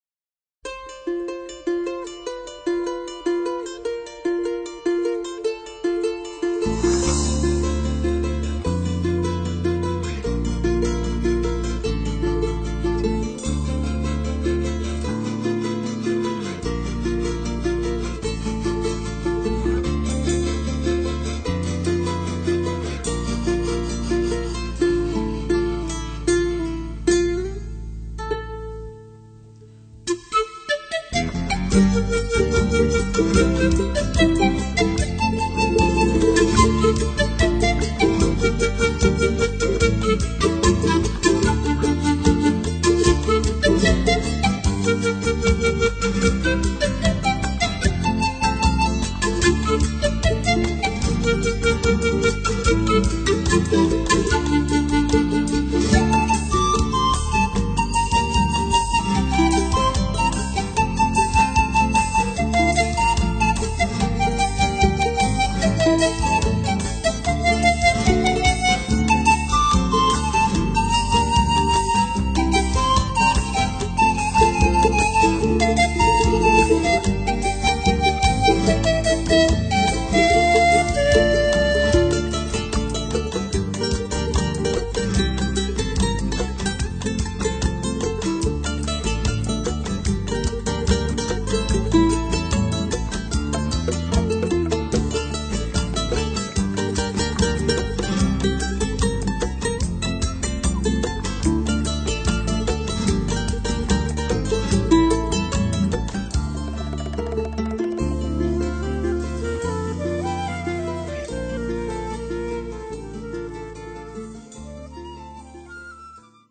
Guitarra
Sikus
Quena
Bajo
Flauta traversa
Batería
Voz
Charango